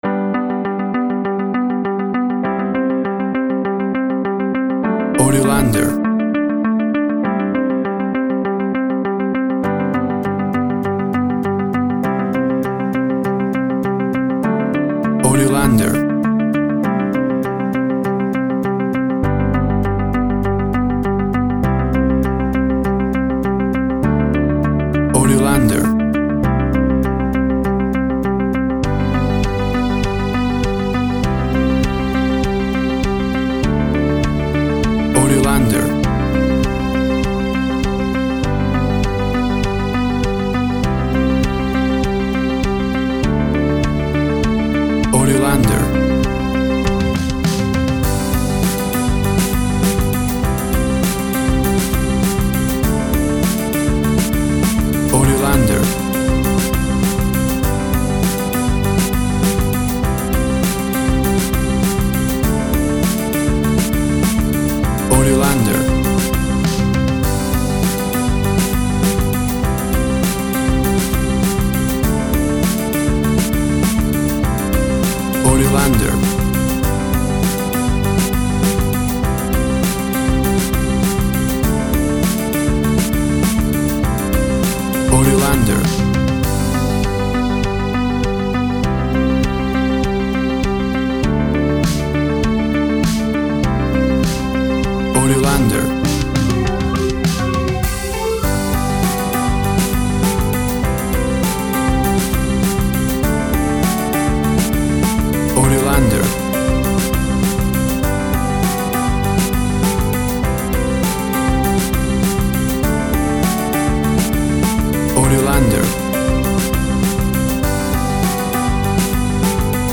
Tempo (BPM) 100